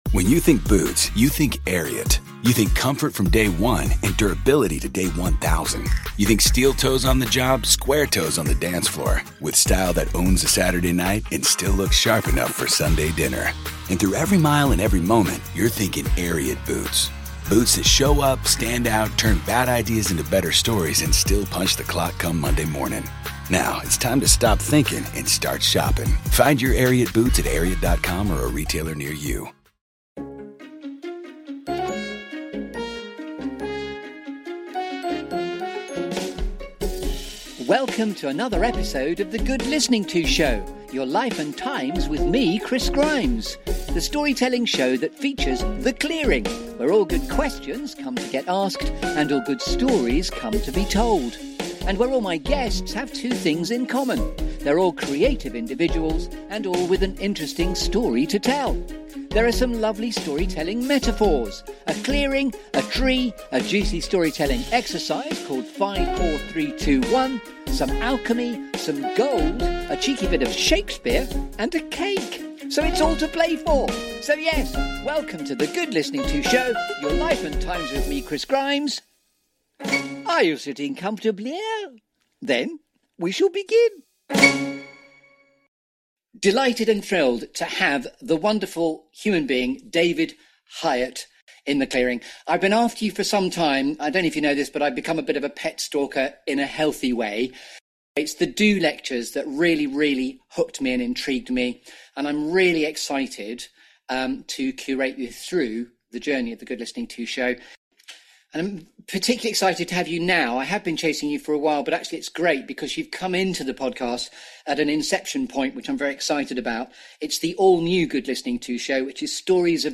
The Good Listening To Show is the Desert Island Discs of UKHR. This feel-good Storytelling Show that brings you ‘The Clearing’.